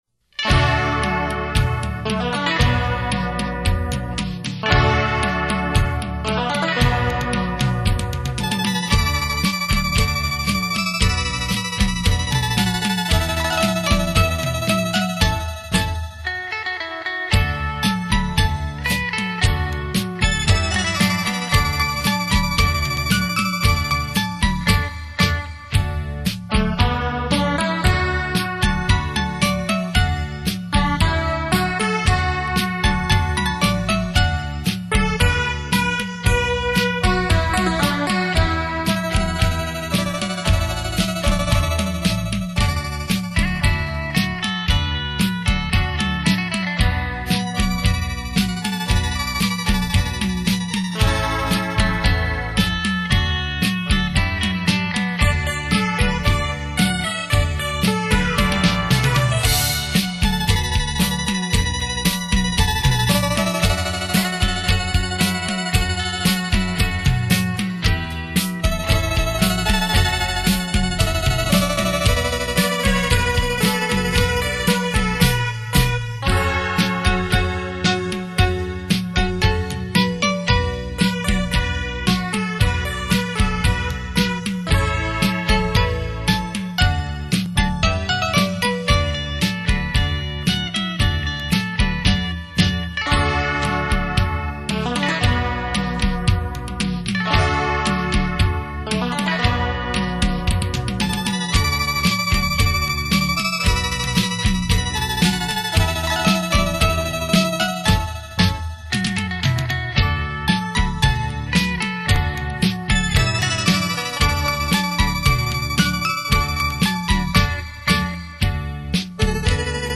[29/6/2009]双电子琴吉他演奏